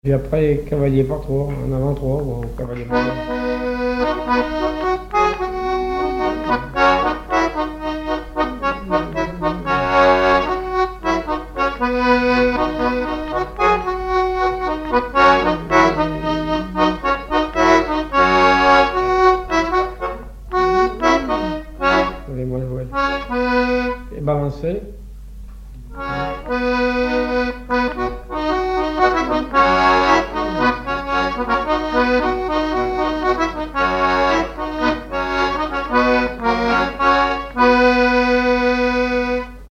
Saint-Hilaire-de-Riez
danse : quadrille : avant-trois
Répertoire sur accordéon diatonique
Pièce musicale inédite